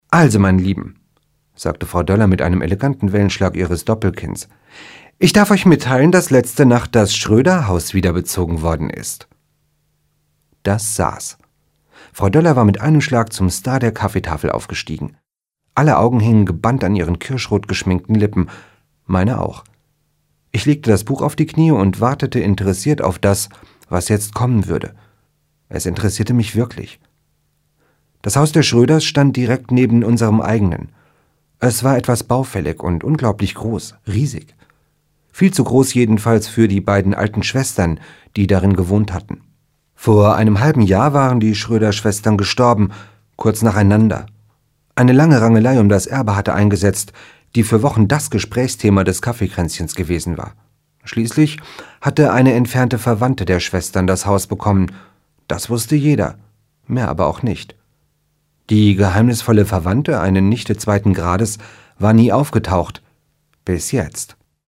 Andreas Steinhöfel (Sprecher)